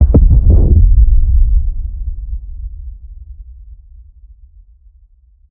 sloth_blowout.ogg